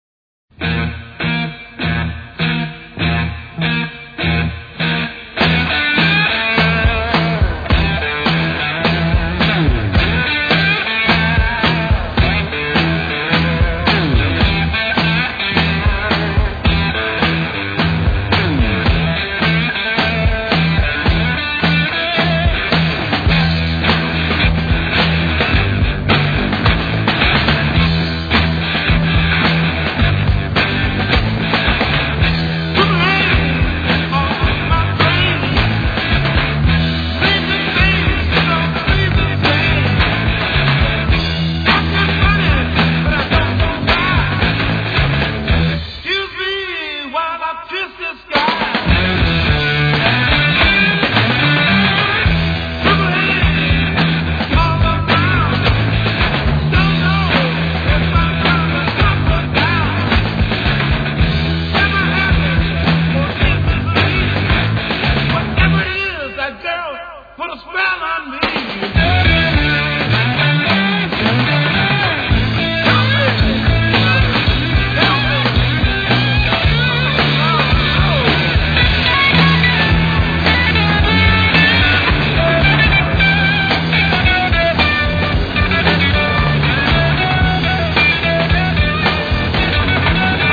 Tout est en place, énergie, inspiration, musicalité…